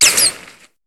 Cri de Mimantis dans Pokémon HOME.